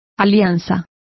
Complete with pronunciation of the translation of covenant.